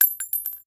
rifle_generic_7.ogg